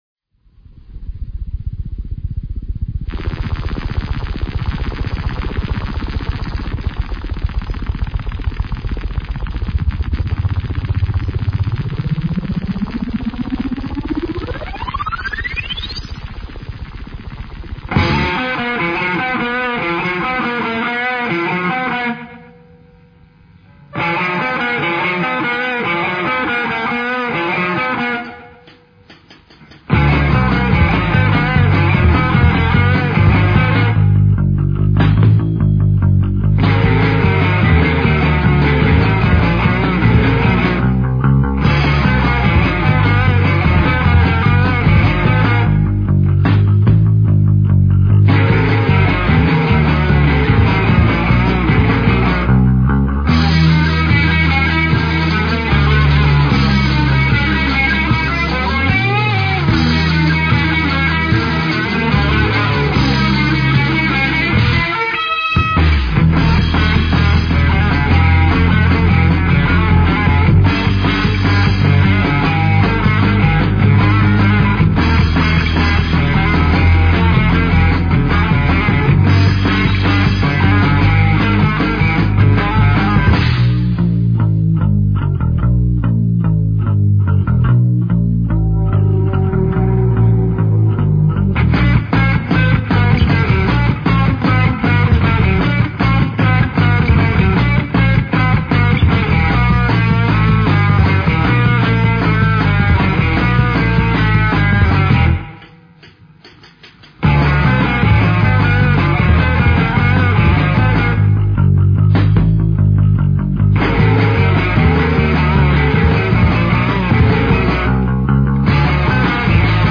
Рок-Панк-Шарм-Попс-группы
клавиши, гитара
ударные
Запись сделана в подвале Института в феврале 1995 года...
Инструментальная композиция.